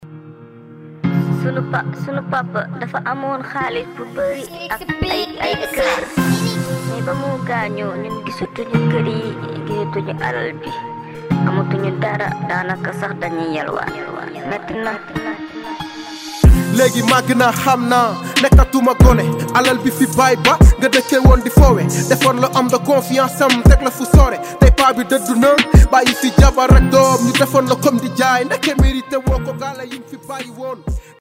Un album 100% Rap mêlant émotions, poésie et mélodies